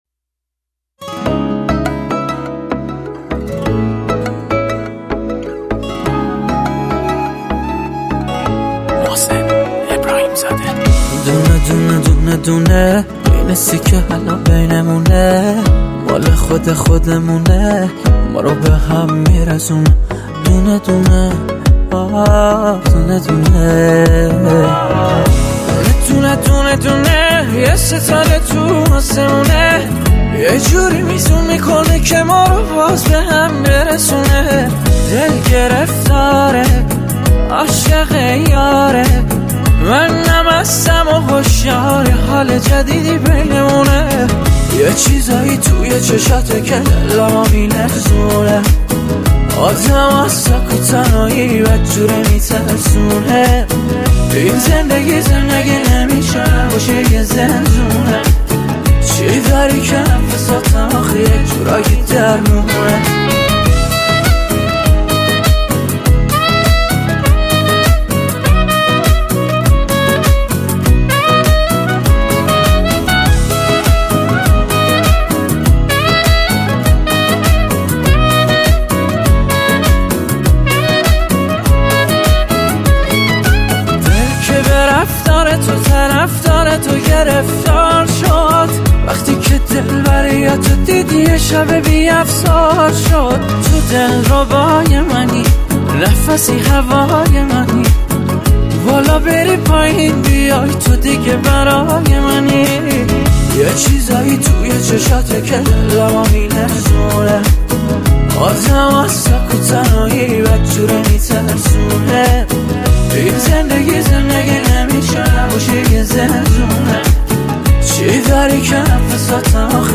با ریتم 6/8